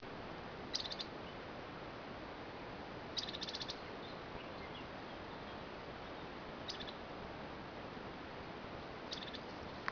c'è un uccellino nascosto tra cespugli ed arbusti, non si fa quasi vedere ma si fa sentire con questi 'tocchi' anche a poca distanza.
Siamo in Toscana, agosto 2012 a 300 m.
Scricciolo ?